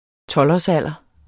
Udtale [ ˈtʌlɒs- ]